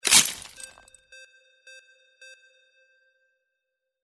Ice Break 04
Stereo sound effect - Wav.16 bit/44.1 KHz and Mp3 128 Kbps
previewIMP_ICE_BREAK_WBSD04.mp3